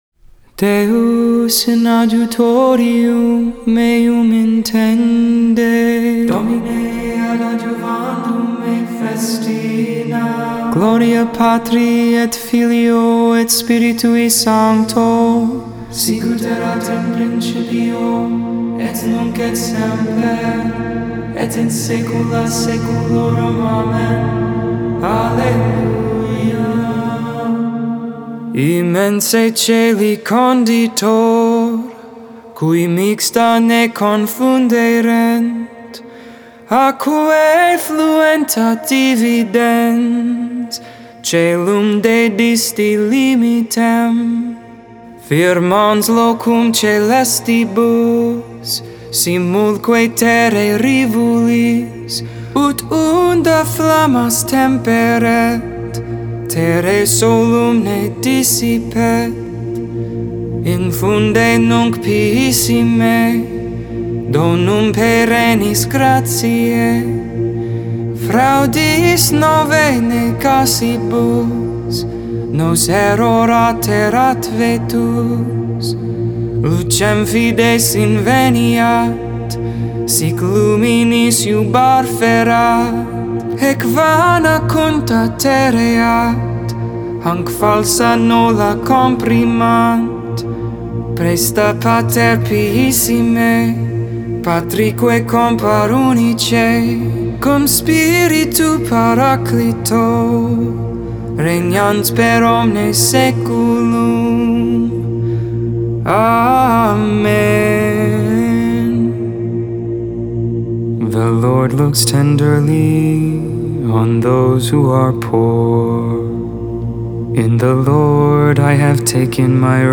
2.8.21 Vespers, Monday Evening Prayer